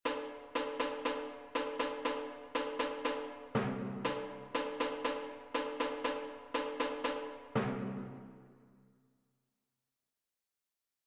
A continuación estarán los audios para escuchar los resultados sonoros de cada línea rítmica.
Tambora
OBtnyiefM0X_Base-ritmica-Tambora.mp3